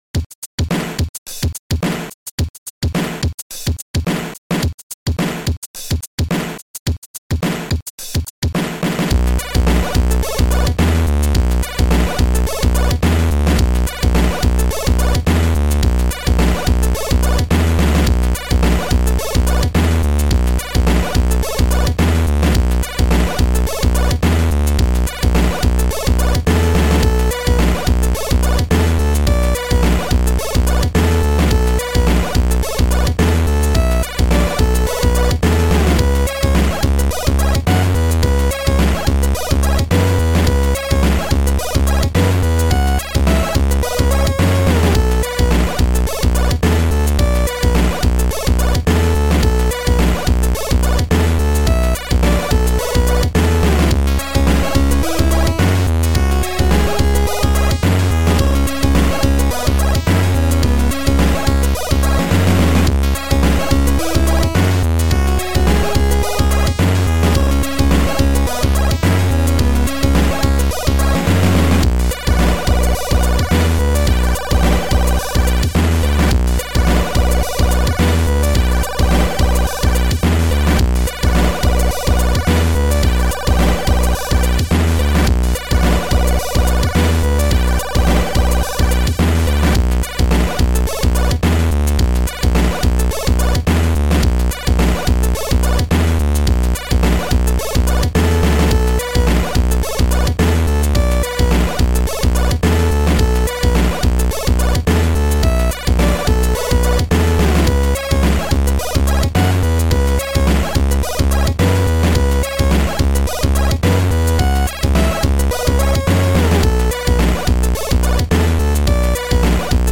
Noisetracker/Protracker